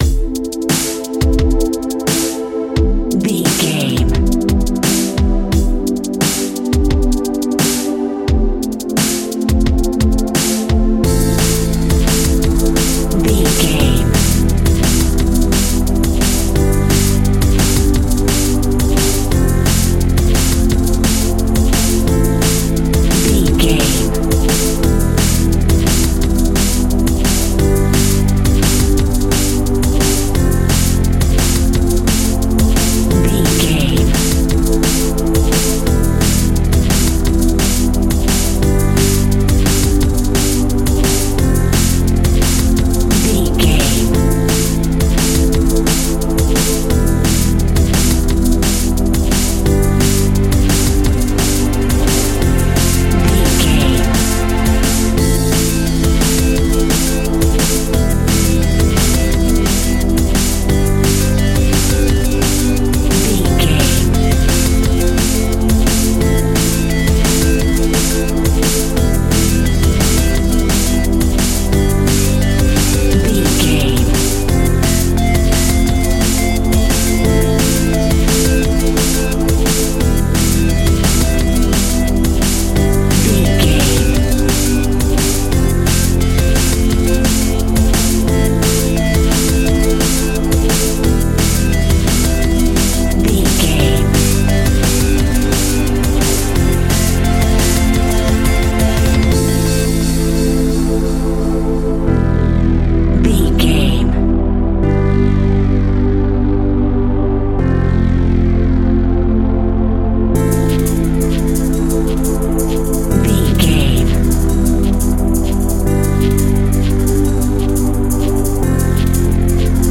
Aeolian/Minor
Fast
futuristic
hypnotic
industrial
frantic
aggressive
synthesiser
drums
electronic
sub bass
synth leads